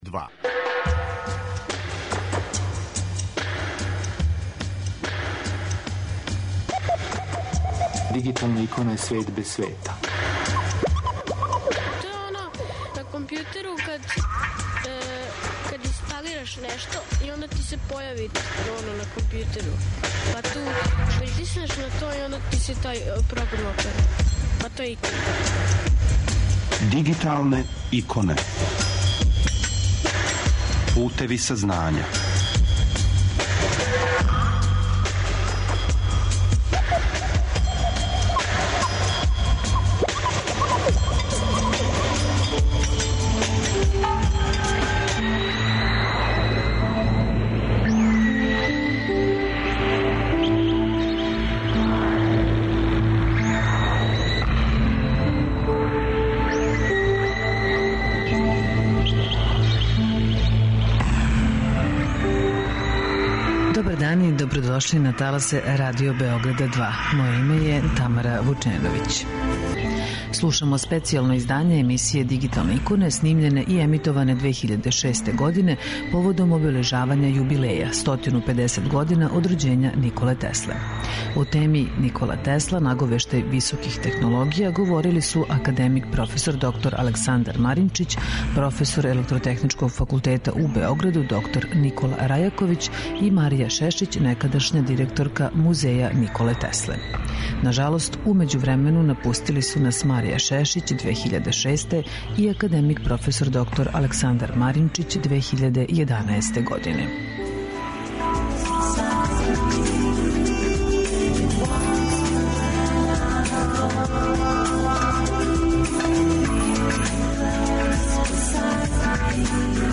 Данас слушамо специјално издање емисије снимљене и емитоване 2006. године поводом обележавања јубилеја - 150 година од рођења Николе Тесле.